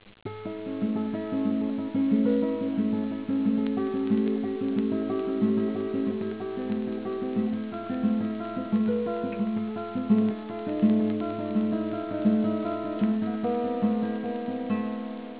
My little guitar page
I just digitized a little 20 second lick that I wrote a while ago as an intro to a song. Keep in mind this was done on a 2-chip homemade digitizer.